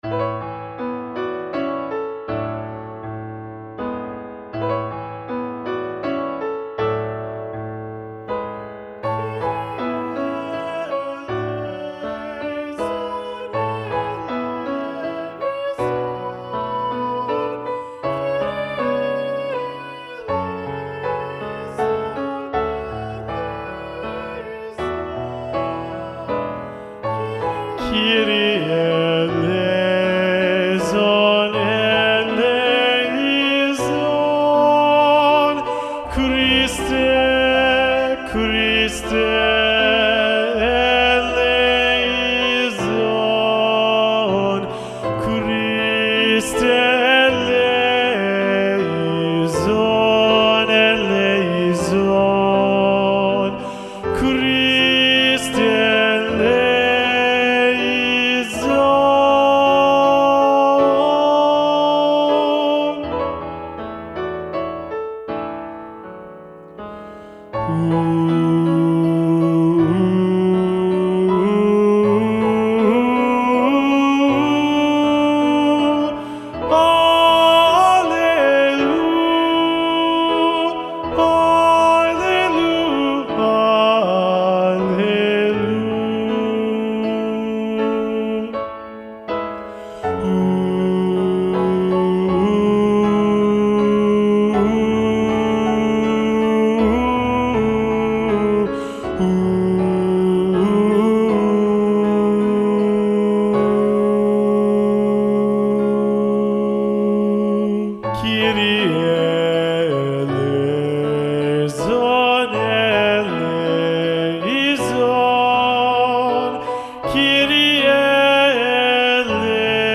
Lo ULTIMO Tenores
Kyrie-Missa-Festiva-SATB-Tenor-Predominant-John-Leavitt.mp3